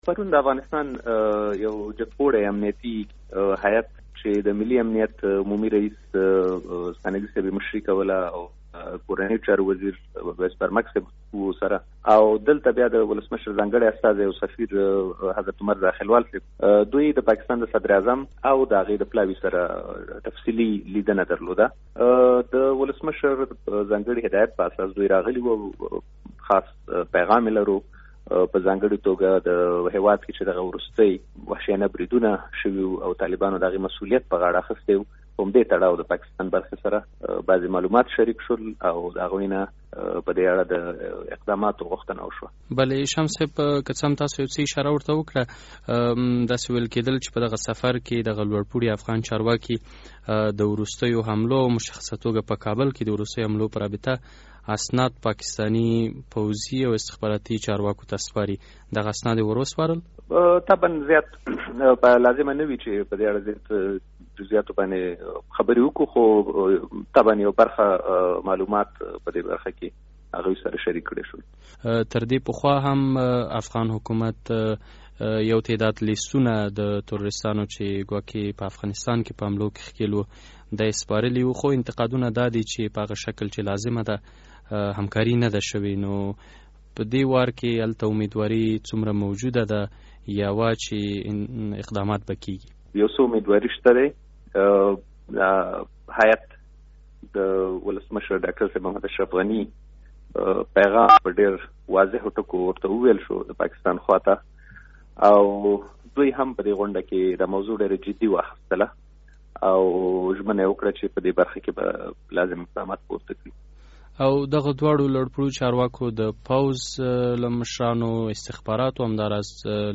مرکه
له ښاغلي شمس سره مرکه